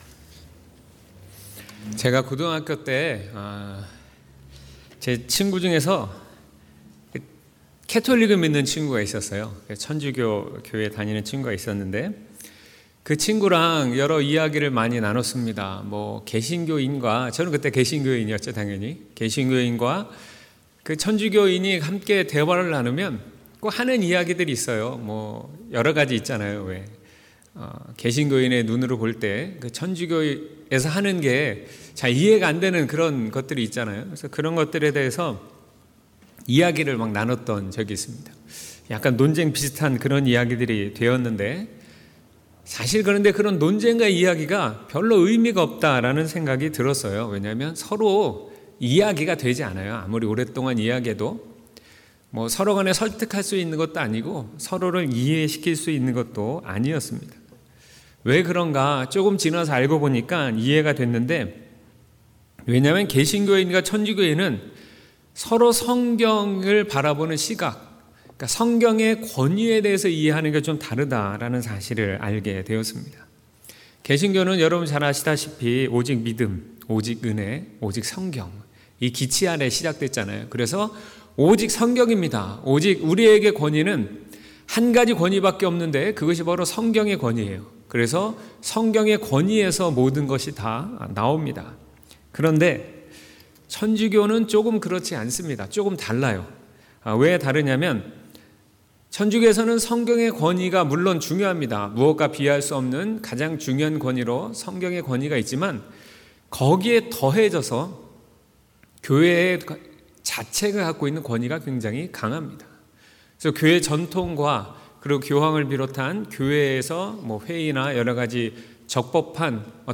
2월24일 주일설교/내발의 등이요 내 길에 빛/시119:105-113